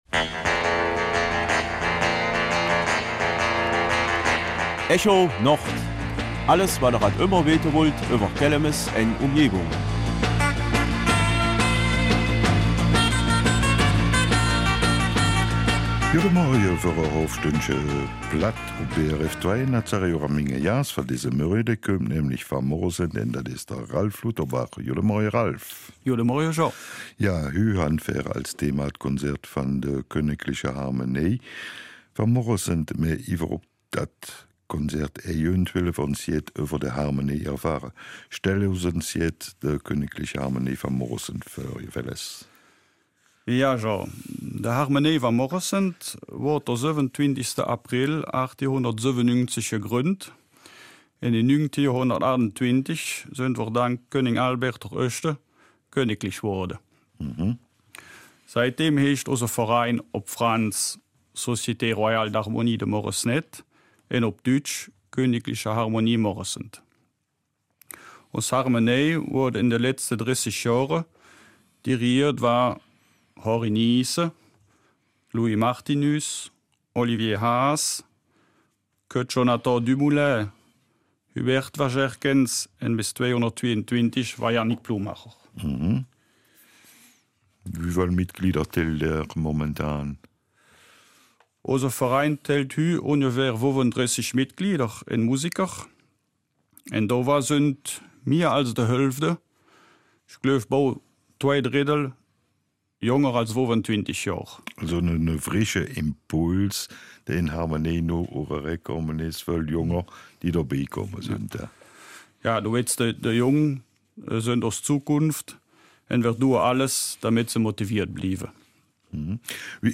Kelmiser Mundart: Konzert ''Notes de douc’heure''